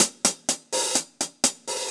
Index of /musicradar/ultimate-hihat-samples/125bpm
UHH_AcoustiHatB_125-03.wav